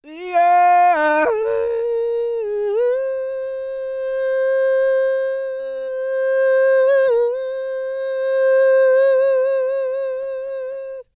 E-CROON 3047.wav